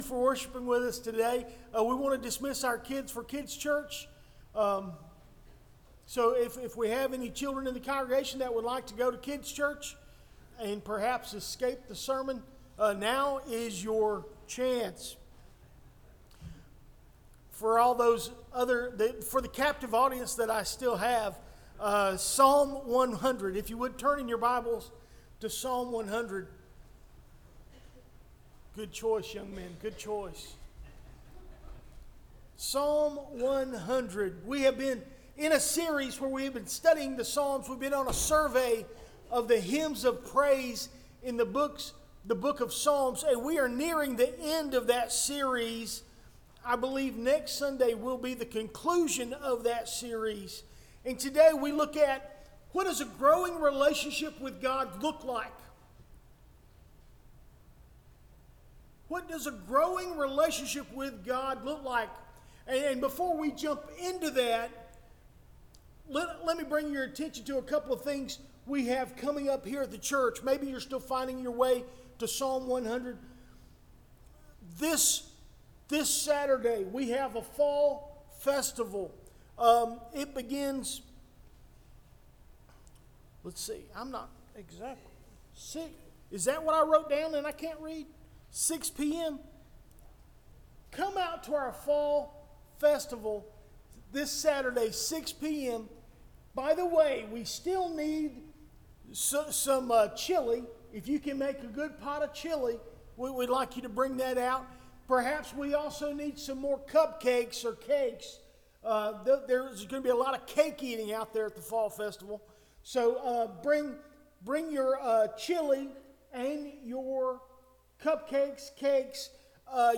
Recent Sermons - Doctor's Creek Baptist Church